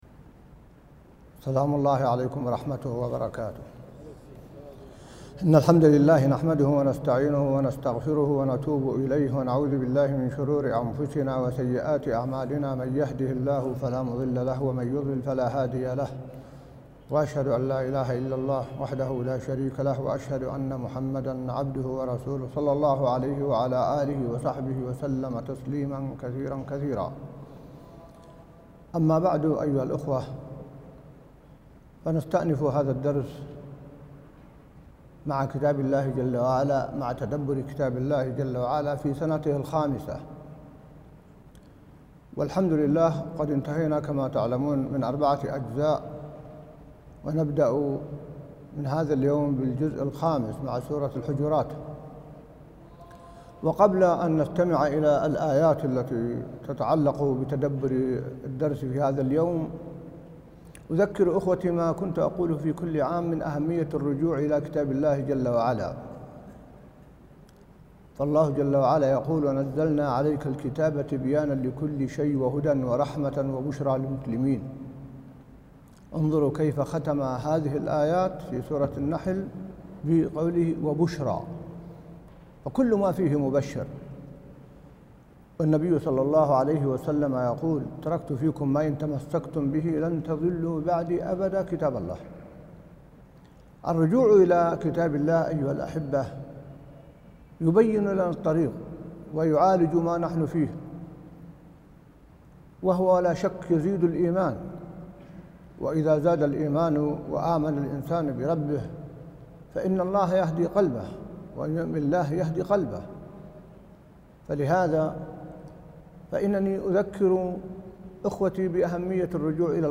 درس ليدبروا آياته 111 | وقفات تدبرية مع سورة الحجرات الجزء الأول | موقع المسلم